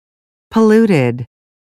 18 polluted (adj) /pəˈluːtɪd/ Ô nhiễm